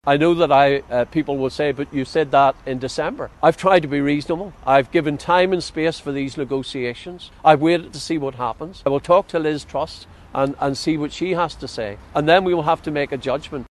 Jeffrey Donaldson – who’s threatened to collapse power-sharing over the issue – says talks can’t keep dragging on……….